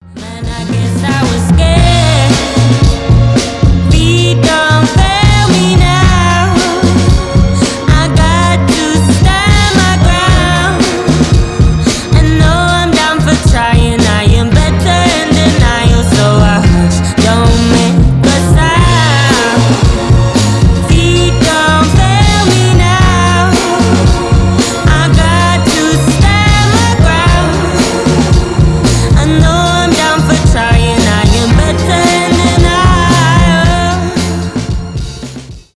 красивый женский голос , поп , соул
rnb , neo soul